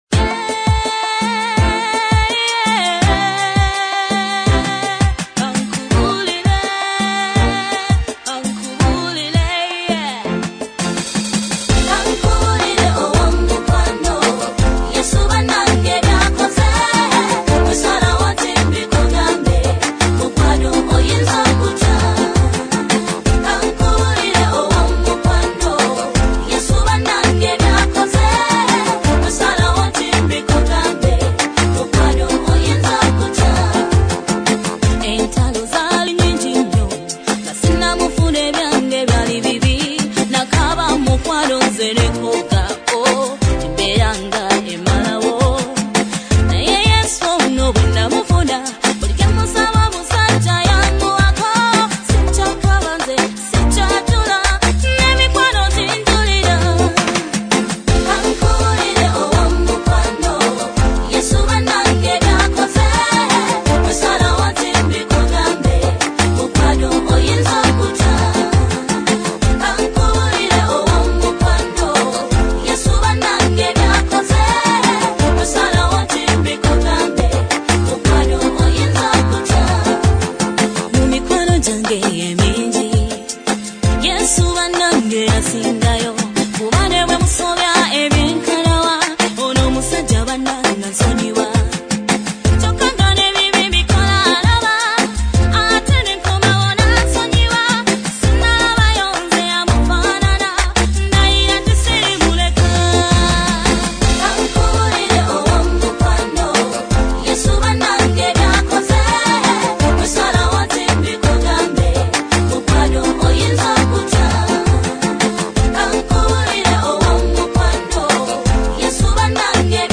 In these two songs you can hear her beautiful voice leading the singing.